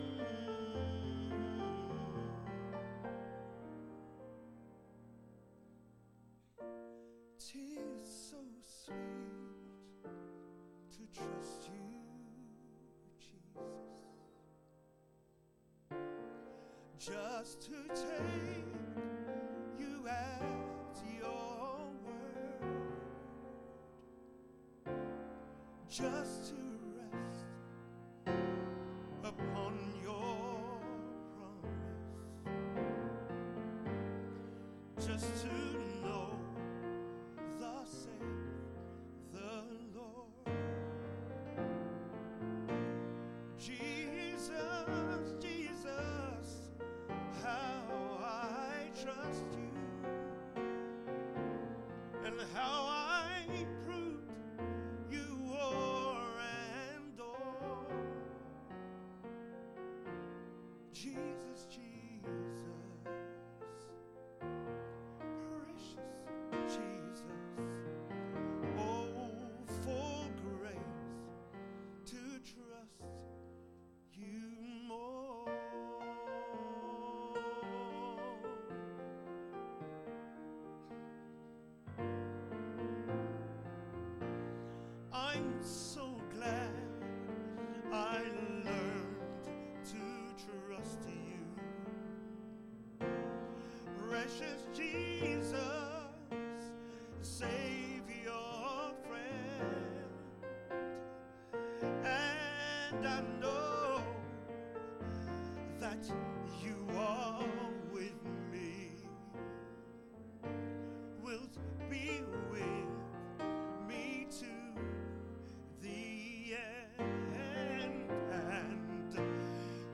Wednesday Evenings Presentation from Camp Meeting 2024